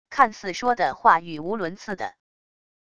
看似说的话语无伦次的wav音频